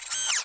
WindowClose.mp3